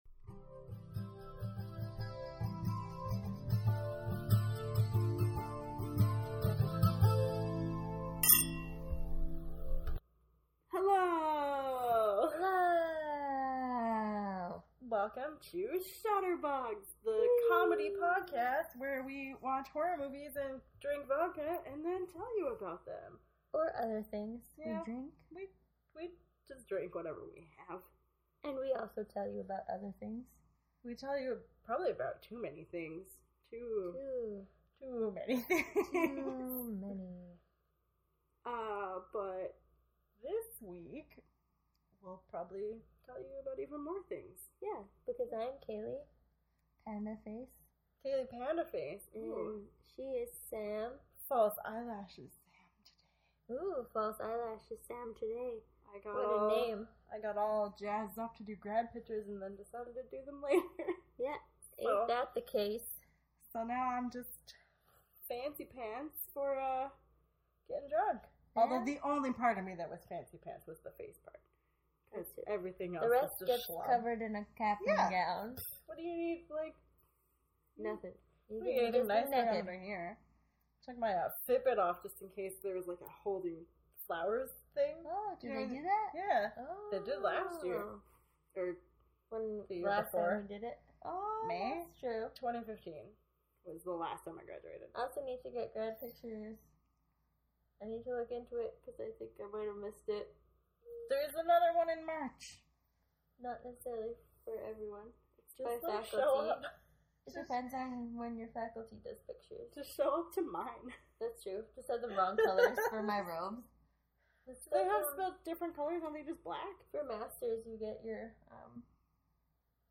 We’re drinking a lot of vodka this round, so enjoy the hilarity.